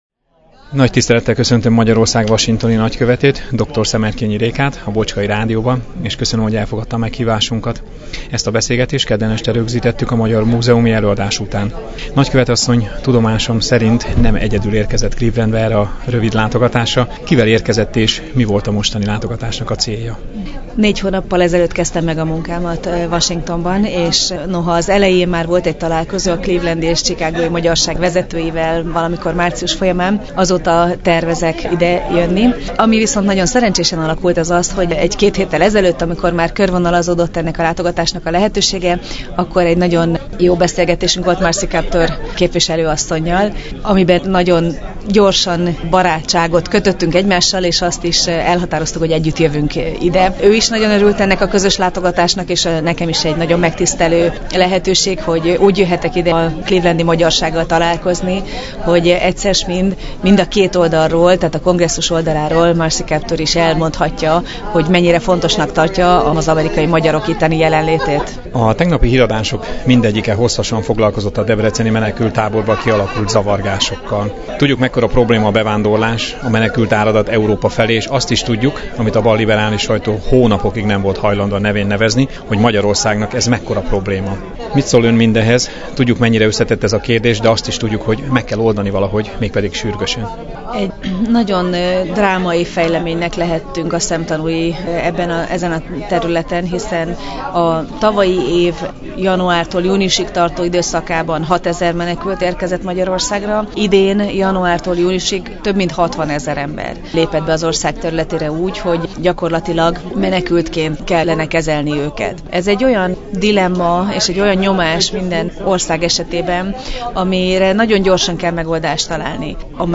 A Bocskai Rádiónak is szívesen állt a rendelkezésére a nagykövetasszony.